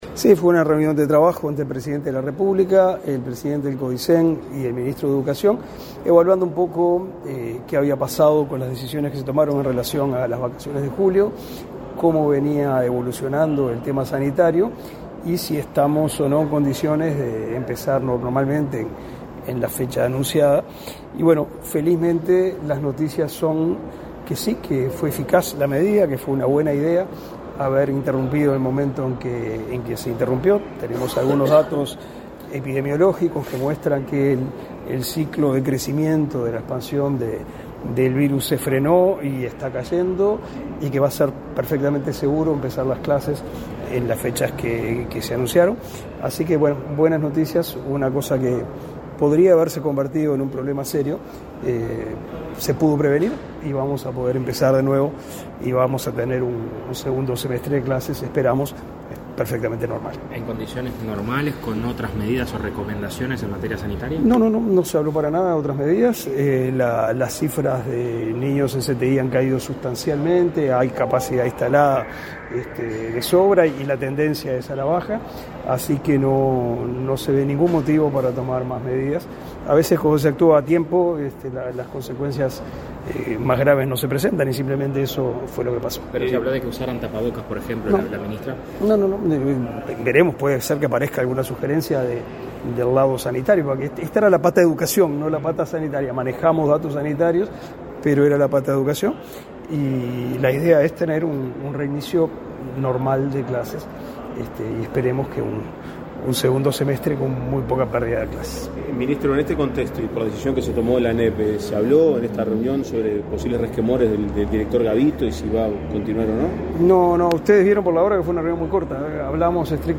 Declaraciones a la prensa del ministro de Educación y Cultura, Pablo da Silveira
Tras una reunión entre el presidente de la República, Luis Lacalle Pou, y autoridades de la enseñanza, este martes 11, la Administración Nacional de Educación Pública resolvió retomar, el próximo lunes 17, los cursos en los niveles de inicial y primaria. El titular del Ministerio de Educación y Cultura, Pablo da Silveira, realizó declaraciones a la prensa.